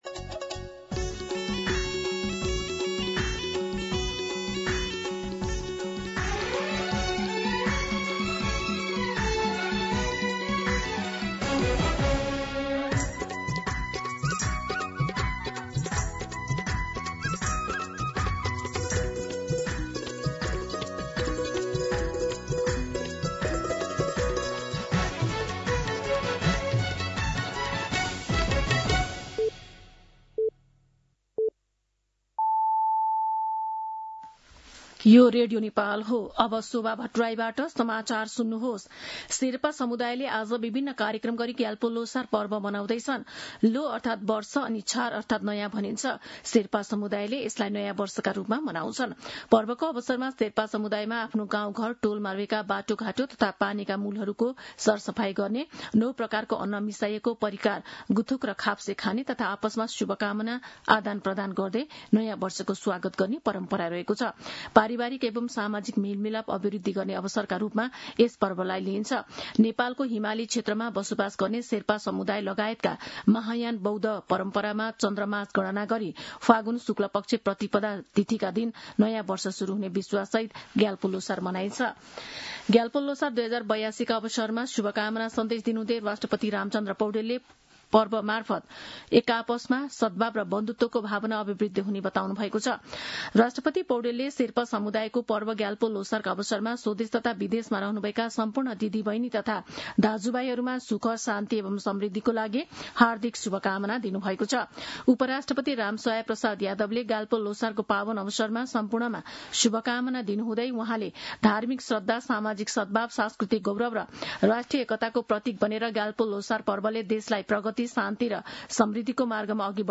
दिउँसो १ बजेको नेपाली समाचार : ६ फागुन , २०८२
1-pm-Nepali-News-3.mp3